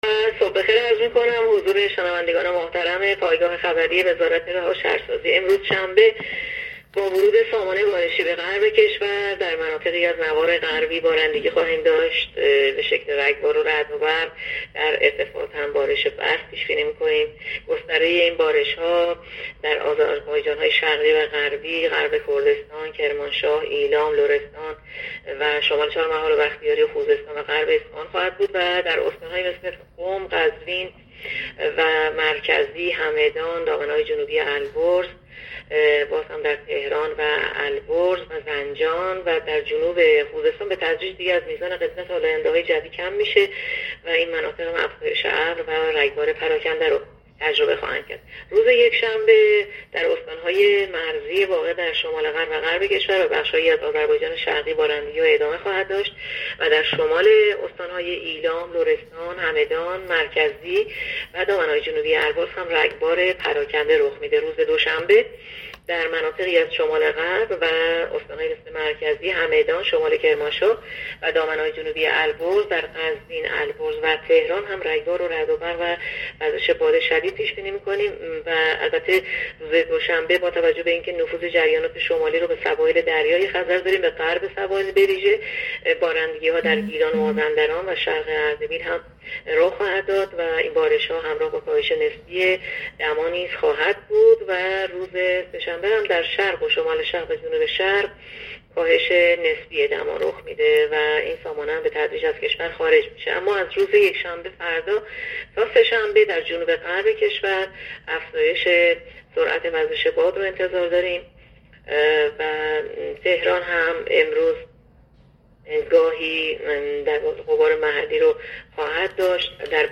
گزارش رادیو اینترنتی پایگاه‌ خبری از آخرین وضعیت آب‌وهوای ۲۴ آبان؛